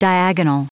Prononciation du mot diagonal en anglais (fichier audio)
Prononciation du mot : diagonal